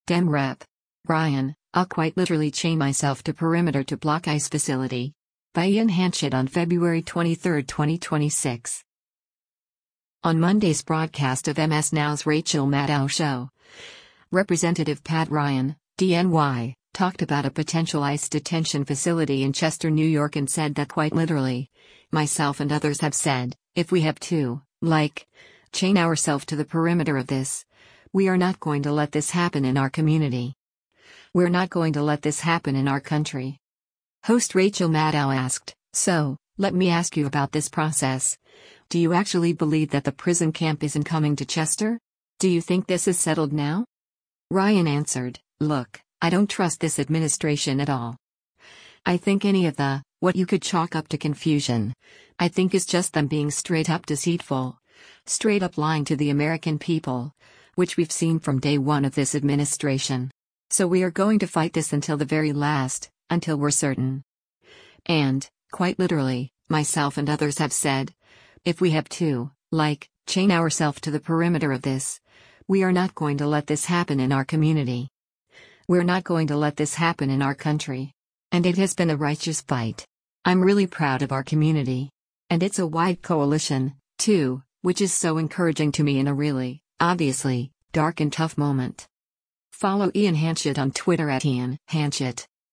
On Monday’s broadcast of MS NOW’s “Rachel Maddow Show,” Rep. Pat Ryan (D-NY) talked about a potential ICE detention facility in Chester, NY and said that “quite literally, myself and others have said, if we have to, like, chain ourself to the perimeter of this, we are not going to let this happen in our community.